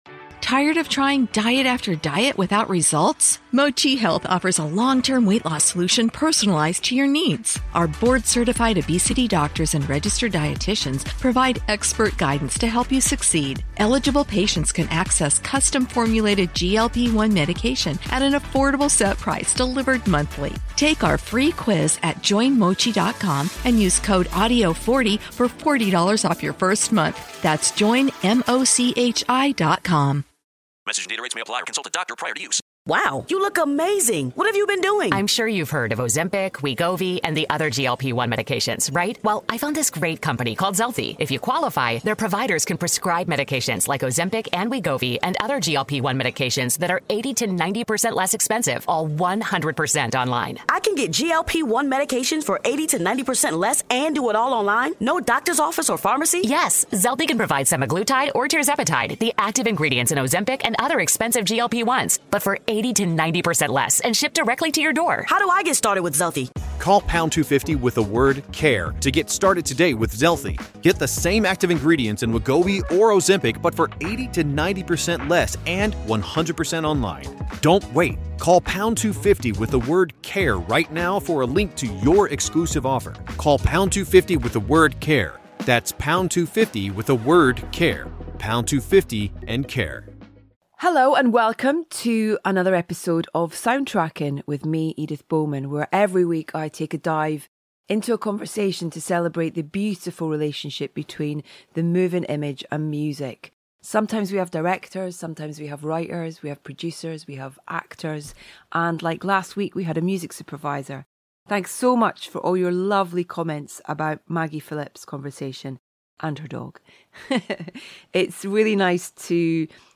Our latest guest on Soundtracking is actor Andrew Scott, who joined Edith in front of an audience as part of our Everyman Soundtracking Film Club to discuss the critically-lauded Netflix series, Ripley.